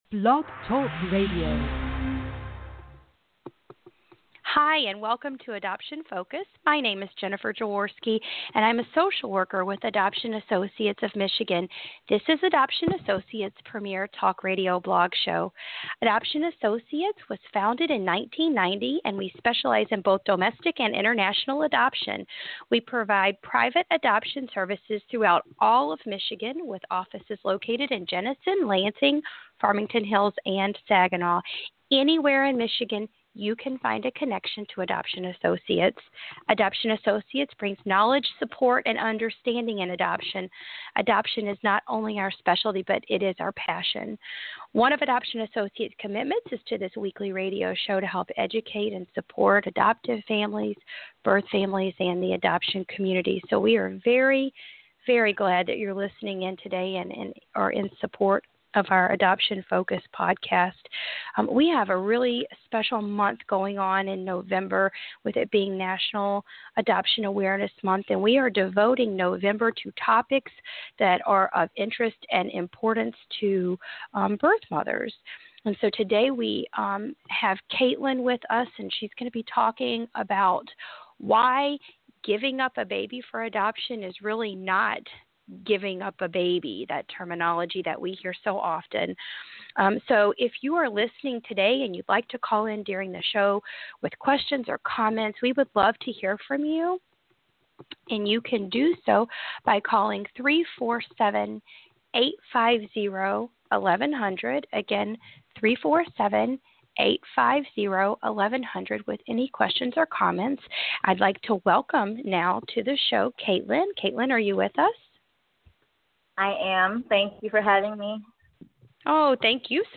Blog Talk Radio Transcript